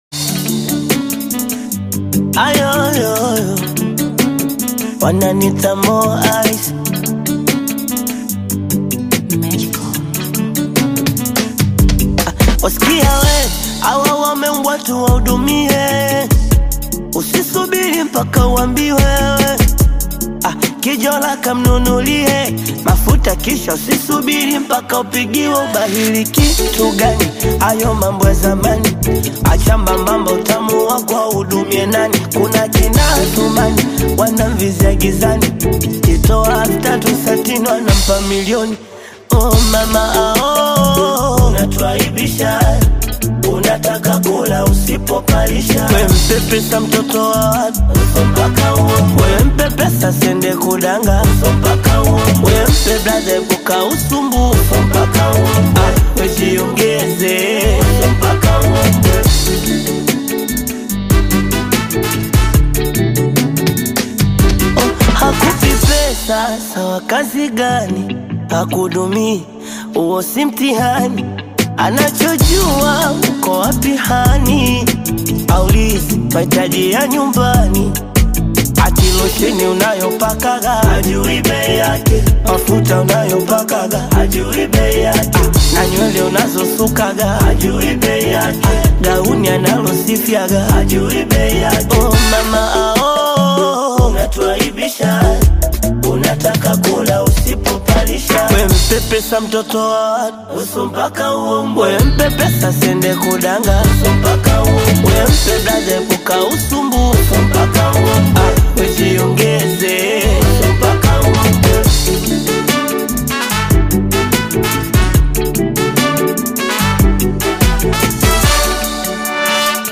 Tanzanian Best upcoming Bongo Flava artist